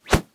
Knife_Whoosh.wav